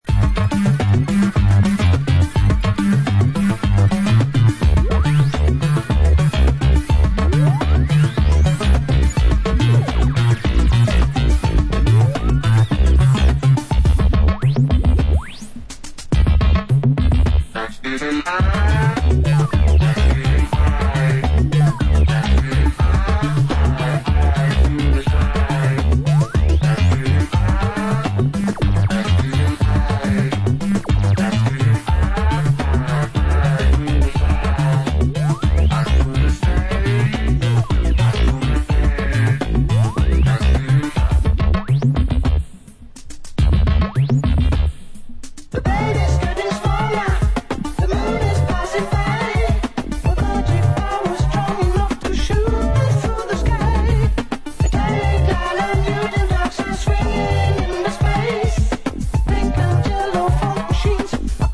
cosmic-electro-disco band